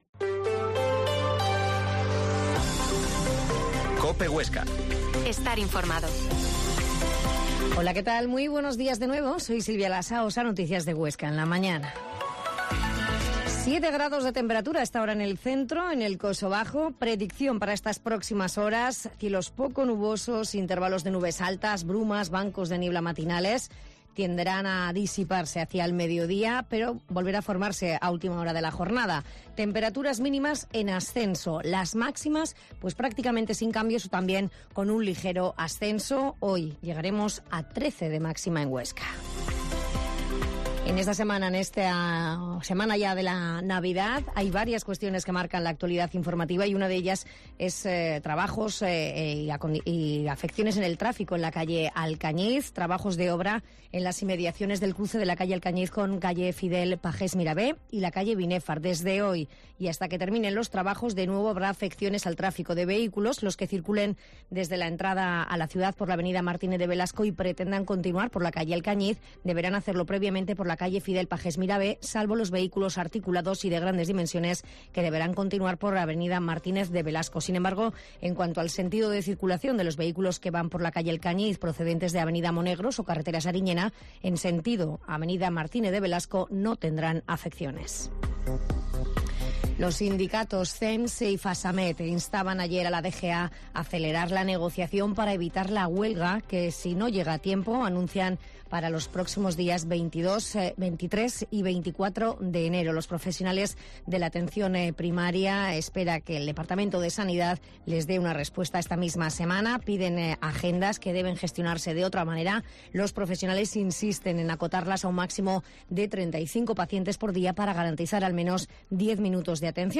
Informativo local